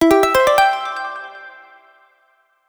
Sound effect of 1-Up in Mario vs. Donkey Kong (Nintendo Switch)